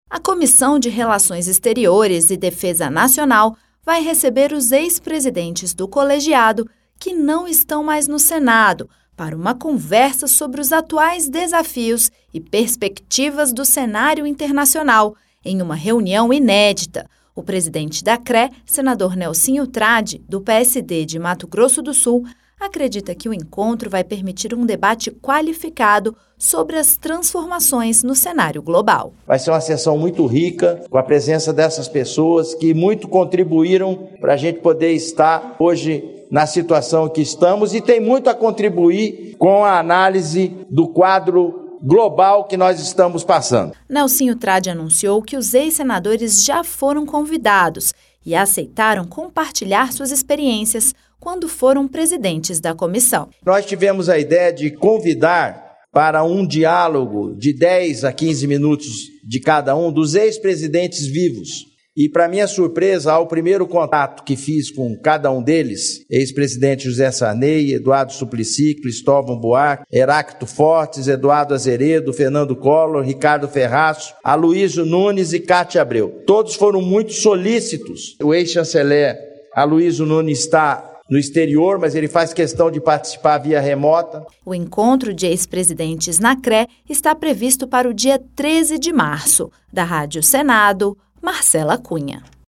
O atual presidente da CRE, Nelsinho Trad, destaca a importância do encontro para um debate qualificado sobre as transformações globais.